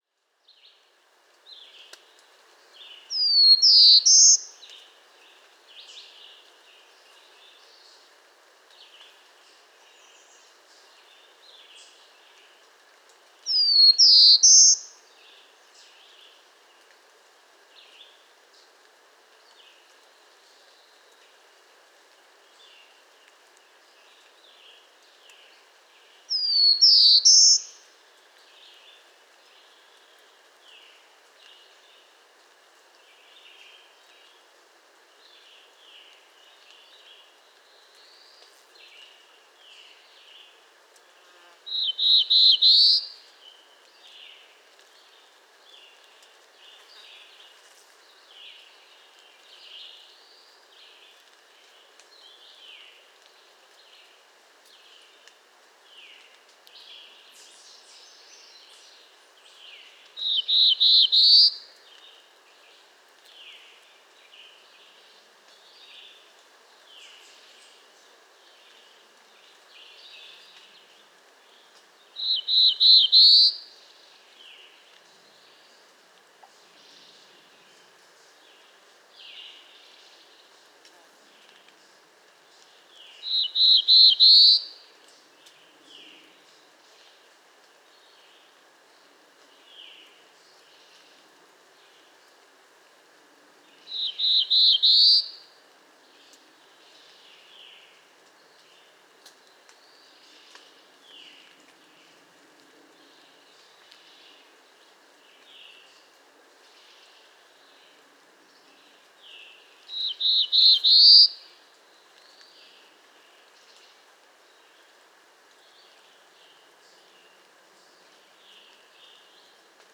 Black-throated Blue Warbler – Setophaga caerulescens
Song – 2 Types Mont-Orford National Park, QC.